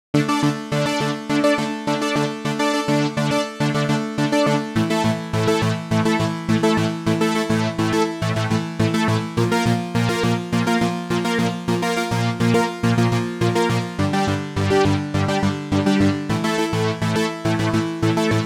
104 BPM Beat Loops Download